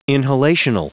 Prononciation du mot inhalational en anglais (fichier audio)
Prononciation du mot : inhalational